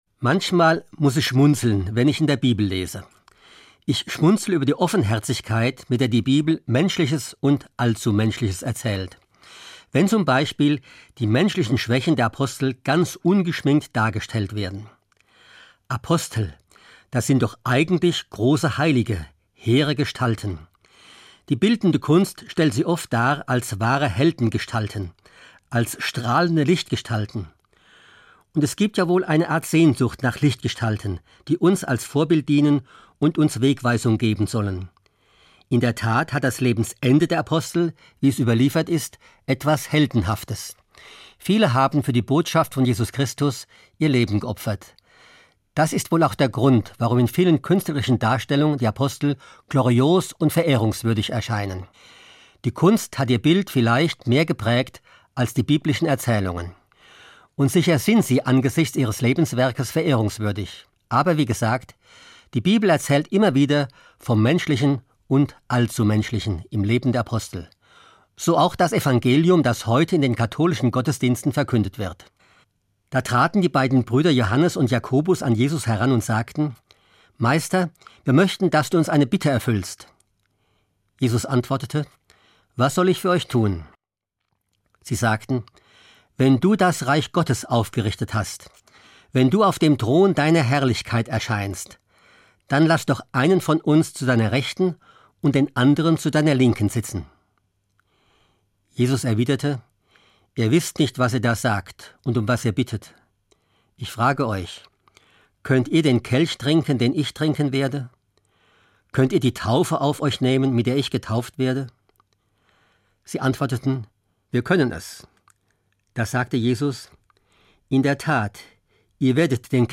hr2 MORGENFEIER